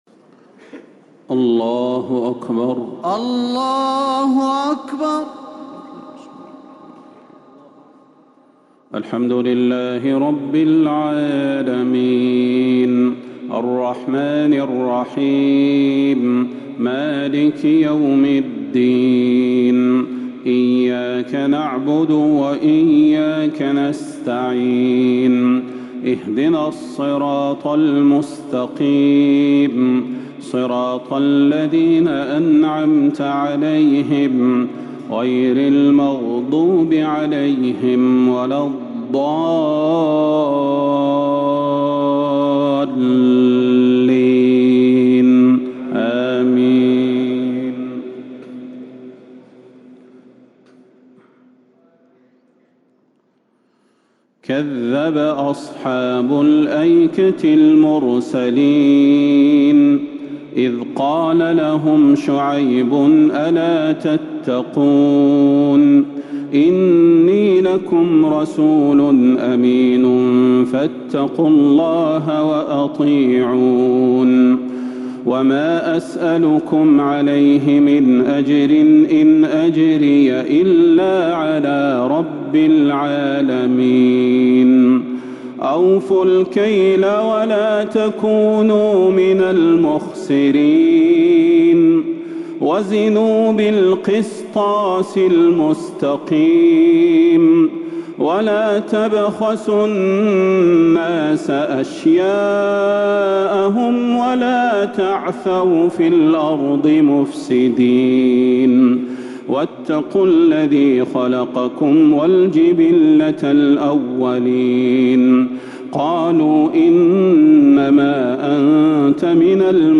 تراويح ليلة 23 رمضان 1444هـ من سورتي الشعراء {176-227} و النمل {1-44} taraweeh 23th night Ramadan1444H surah Ash-Shuara and An-Naml > تراويح الحرم النبوي عام 1444 🕌 > التراويح - تلاوات الحرمين